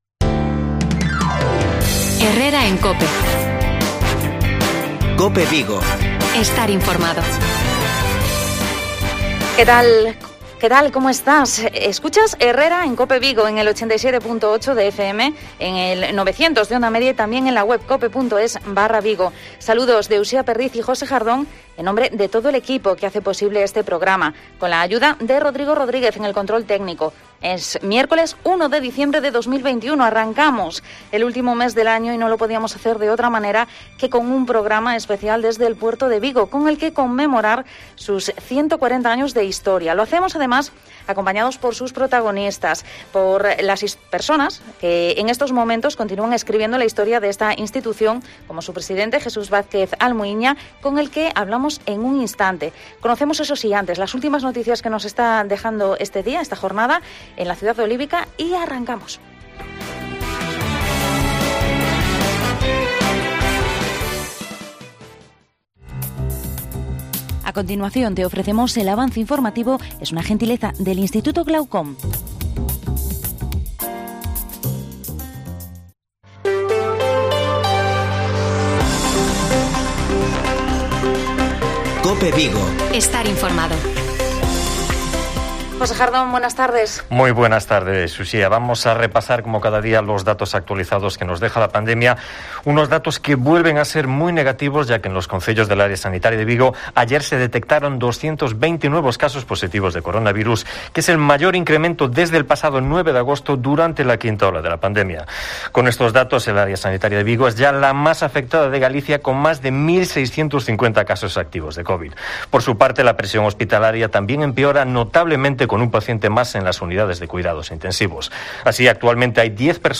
AUDIO: PROGRAMA ESPECIAL DESDE LA AUTORIDAD PORTUARIA DE VIGO POR SU 140 ANIVERSARIO